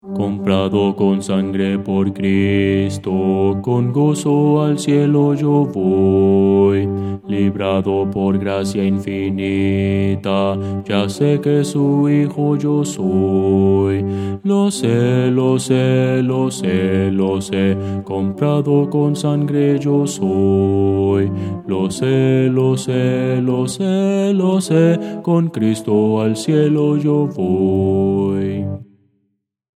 Voces para coro
Bajo – Descargar